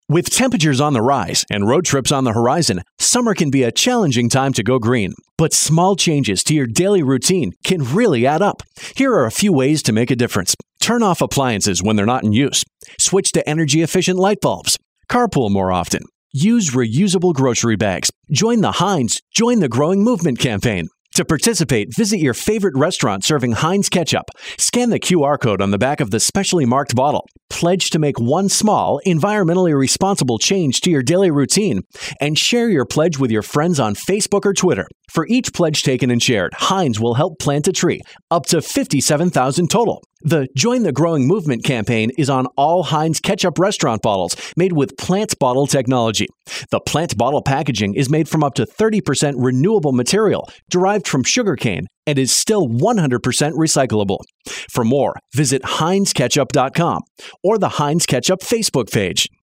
July 20, 2012Posted in: Audio News Release